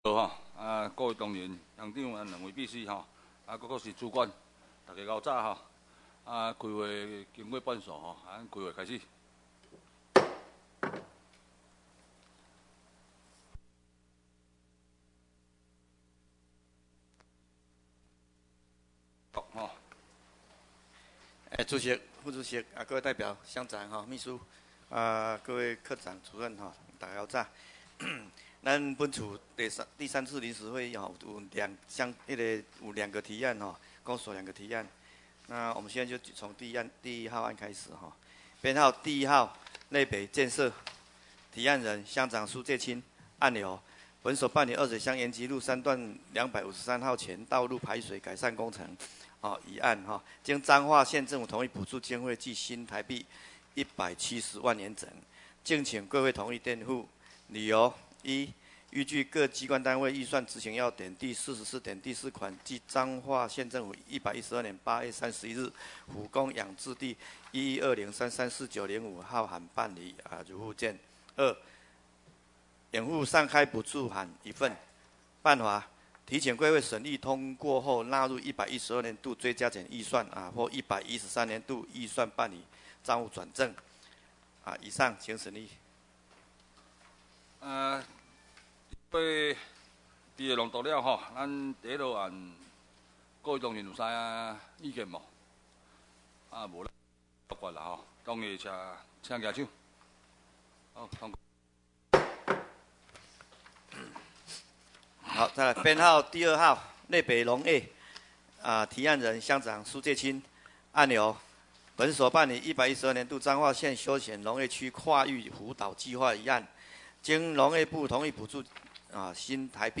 第22屆代表會議事錄音檔